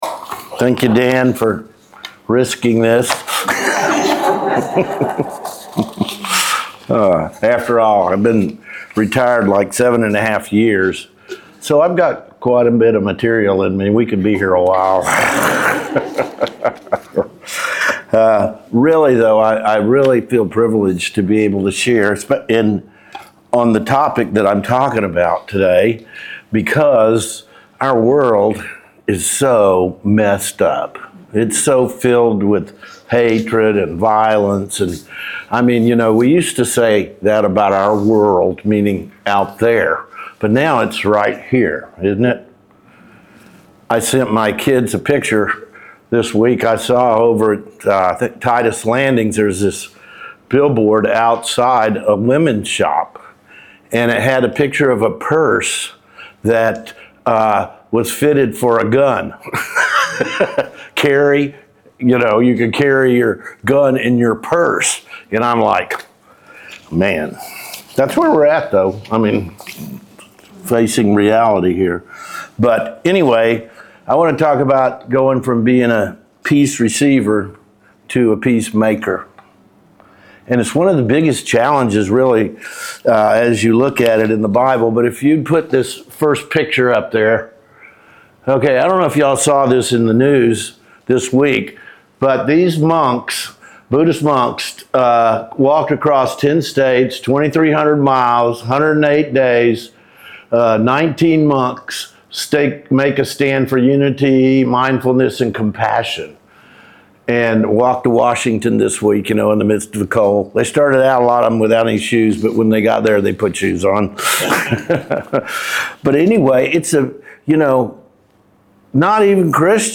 Sermons | The Gathering Church
Guest Speaker